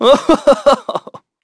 Evan-Vox_Happy2.wav